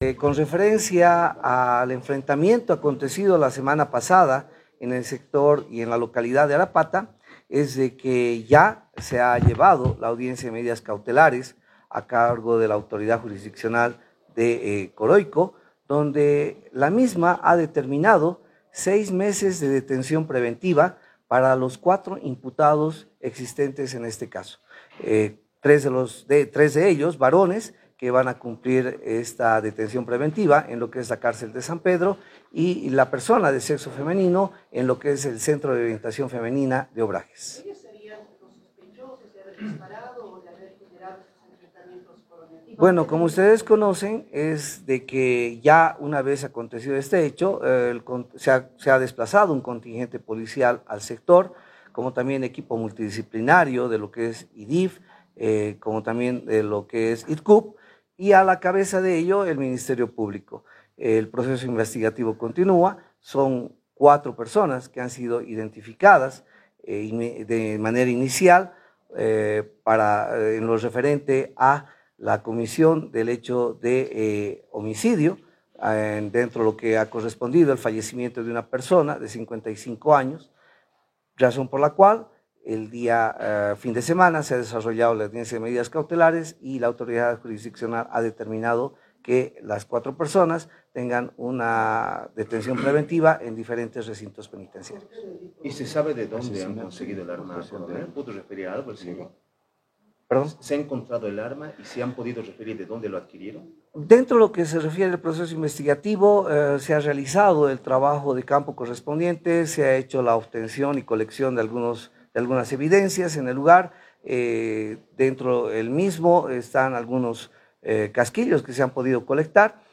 Conferencia de prensa Gunter Agudo - Comandante Dptal. Policia La Paz